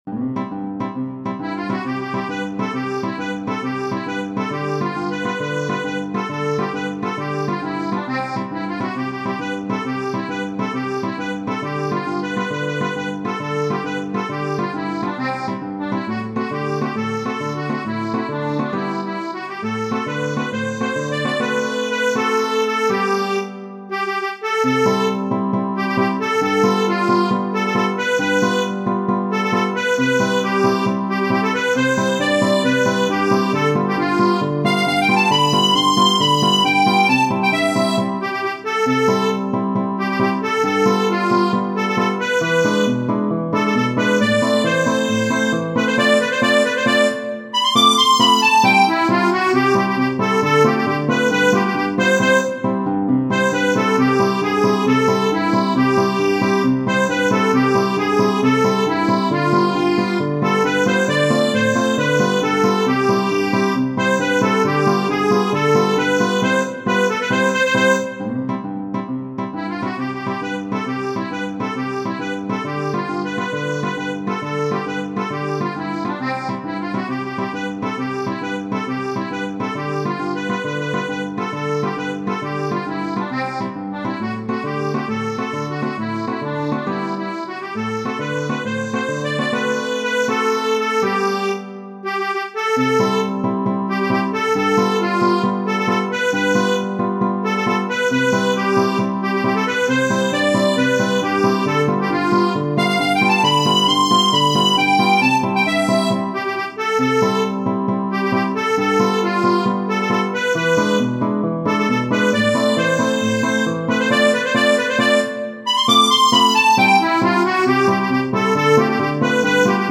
Genere: Napoletane